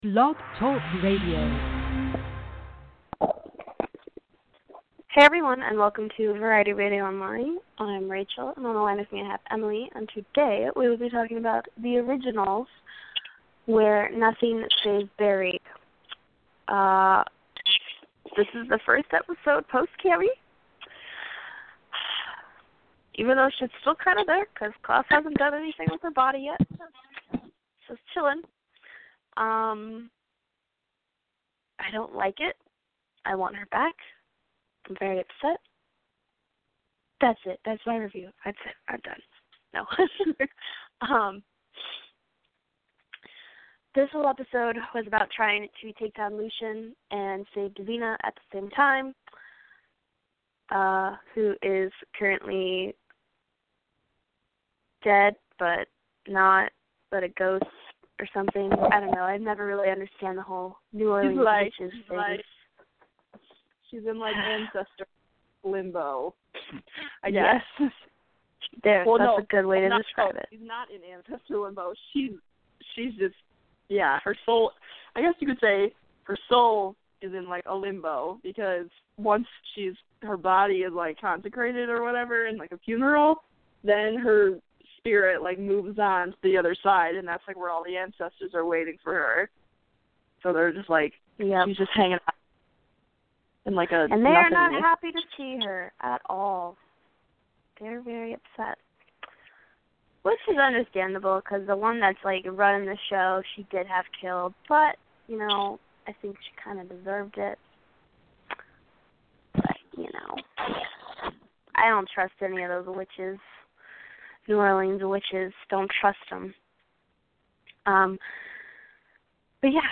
Join The Originals hosts as they discuss the highlights of the latest episode of The Originals.